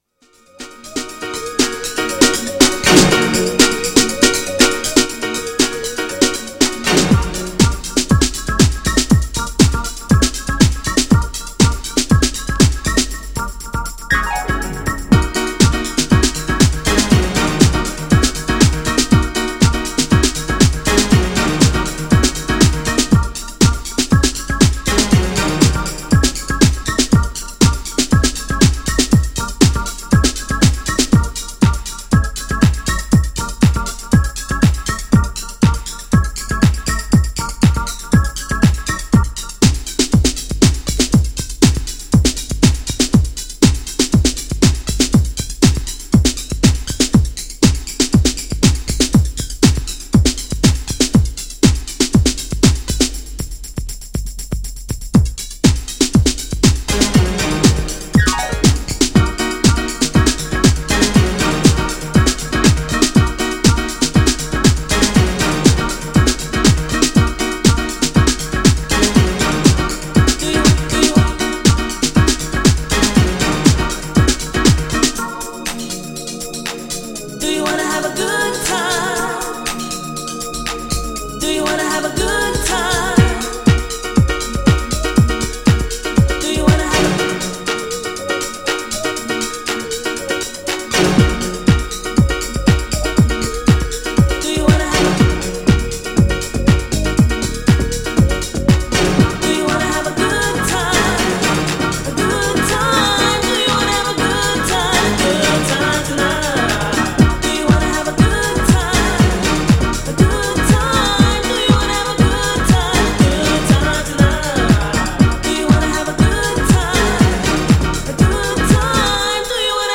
DEEP HOUSE CLASSIC